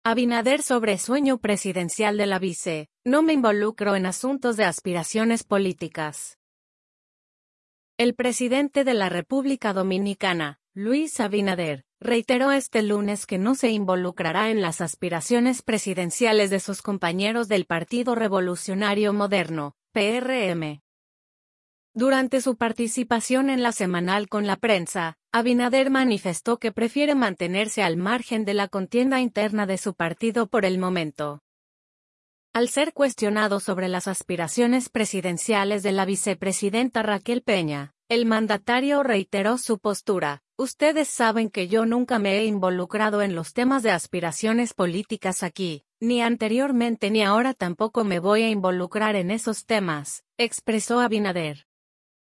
Durante su participación en La Semanal con la Prensa, Abinader manifestó que prefiere mantenerse al margen de la contienda interna de su partido por el momento.